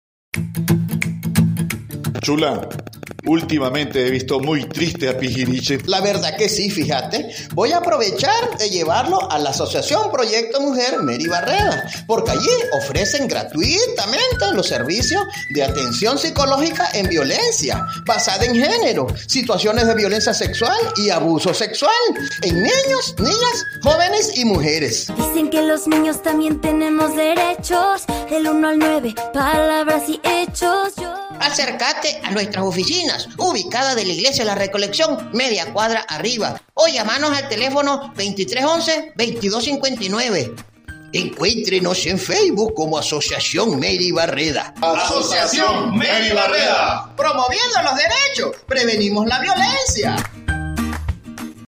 Como parte de la responsabilidad social compartida periodistas, comunicadoras y comunicadores sociales integrados en los procesos de formación que desarrolla la Asociación Mary Barreda sobre el abordaje de las Noticias con Enfoque de Derechos, elaboraron viñetas radiales en prevención de la violencia basada en género, abuso sexual y servicios que ofrece la organización.